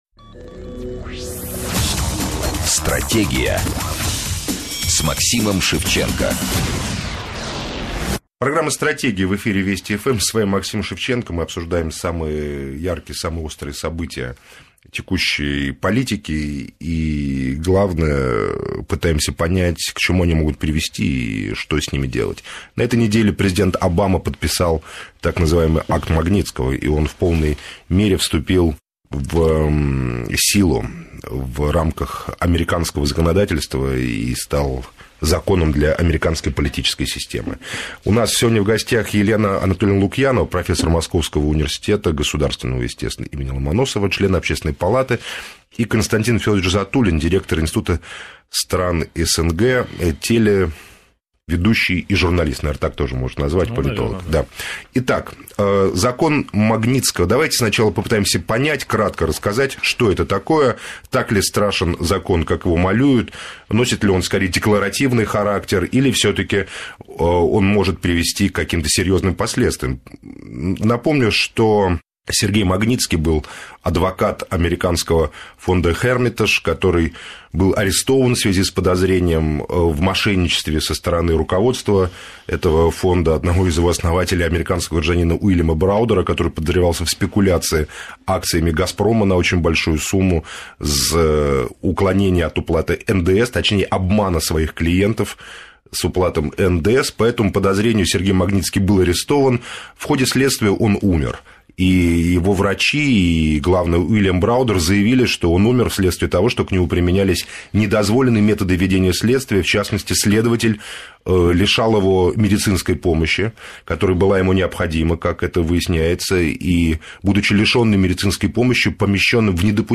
Так ли страшен «закон Магнитского»? Об этом Максим Шевченко беседовал с членом Общественной палаты Еленой Лукьяновой и директором Института стран СНГ Константином Затулиным в программе «Стратегия» на радио «Вести ФМ».